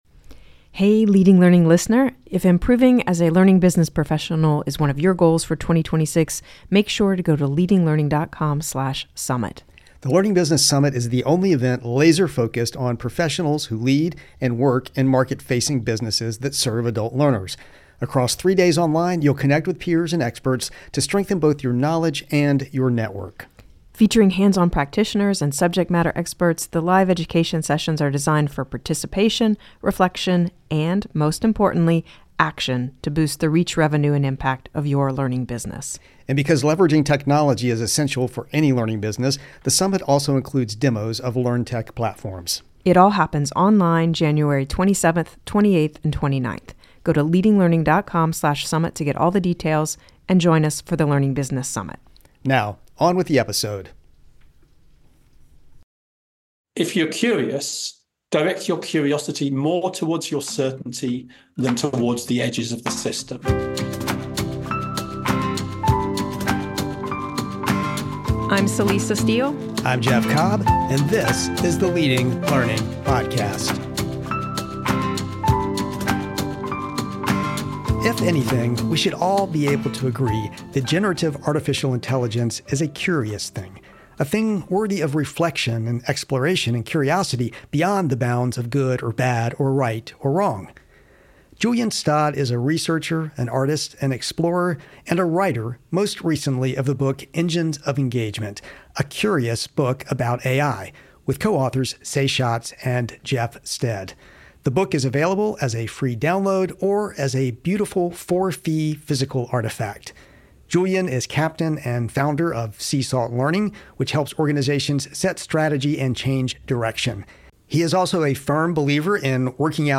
A Curious Conversation About AI